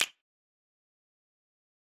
フリー効果音：スナップ
よくある指パッチンの音です！シーンの切り替えの効果音にぴったり！
snap.mp3